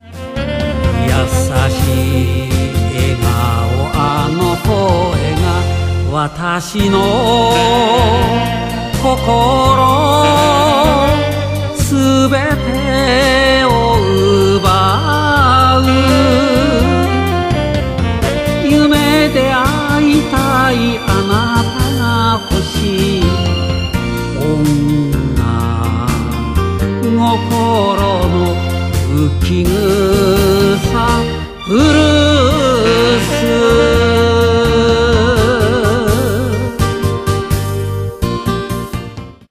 詞に合った無理のないメロディーが落ち着いて聞いていられる。